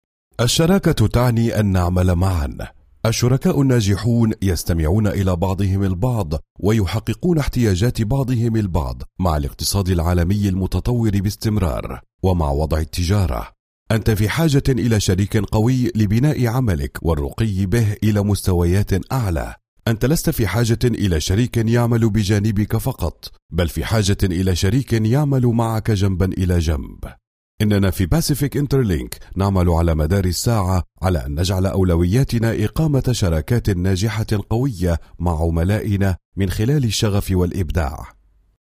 Native speaker Male 30-50 lat
Nagranie lektorskie